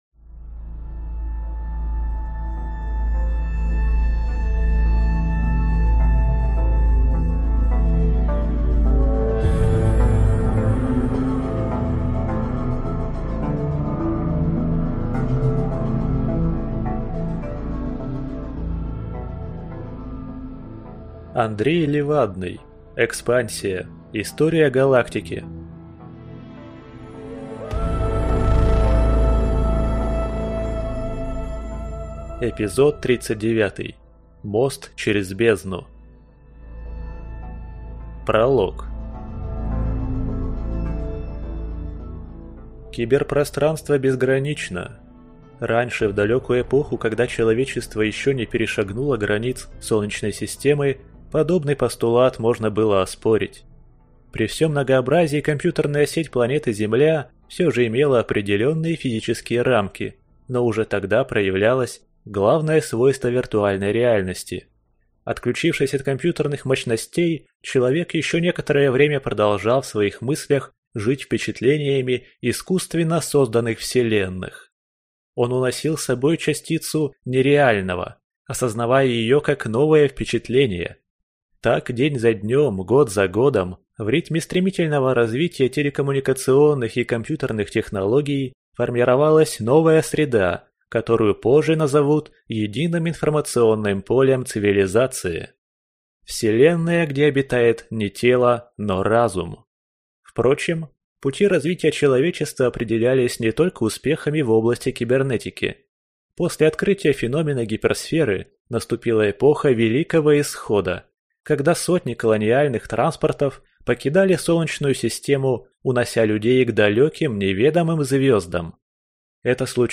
Аудиокнига Мост через Бездну | Библиотека аудиокниг